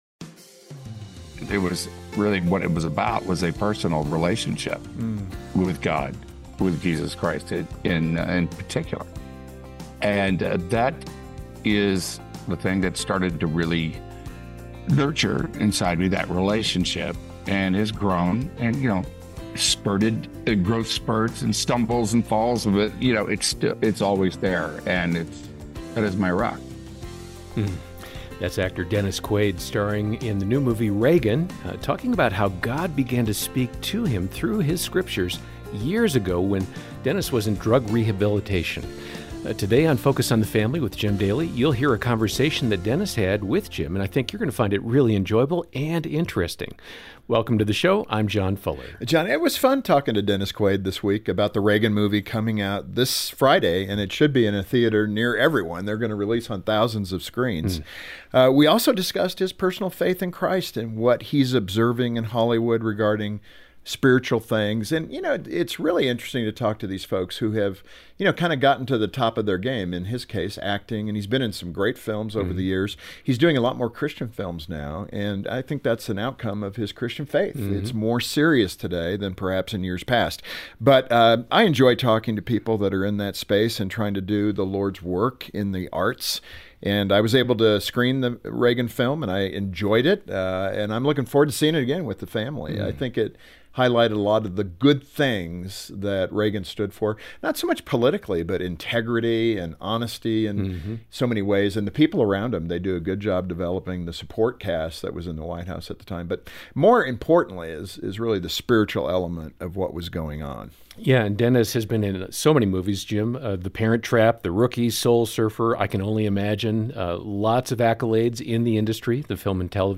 Dennis Quaid joins Focus on the Family for an exclusive interview about his portrayal of Ronald Reagan in the upcoming film. The actor discusses his experience bringing the iconic president to life and shares insights into Reagan's faith and legacy.